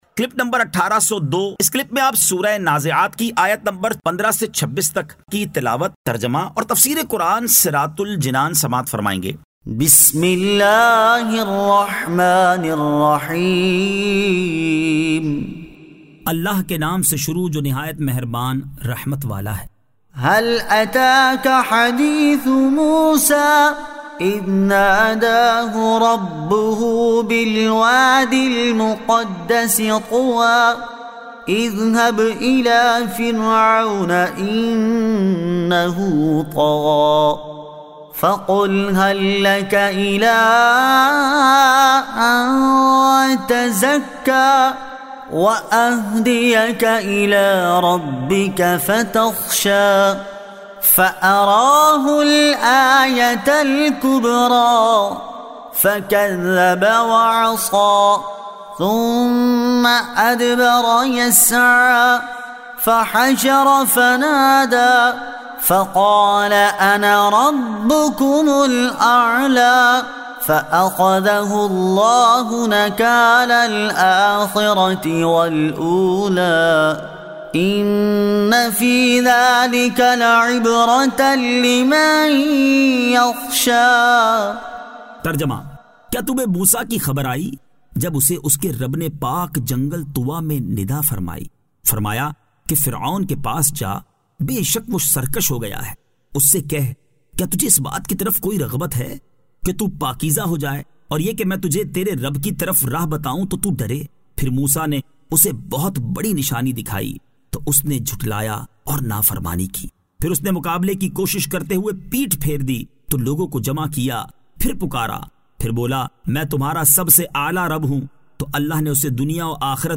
Surah An-Nazi'at 15 To 26 Tilawat , Tarjama , Tafseer